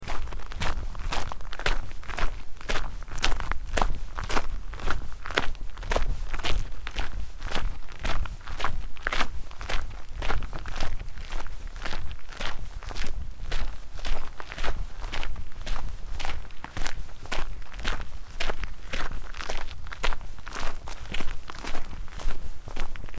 دانلود آهنگ کوه 8 از افکت صوتی طبیعت و محیط
جلوه های صوتی
دانلود صدای کوه 8 از ساعد نیوز با لینک مستقیم و کیفیت بالا